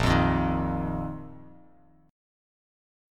A#sus4 chord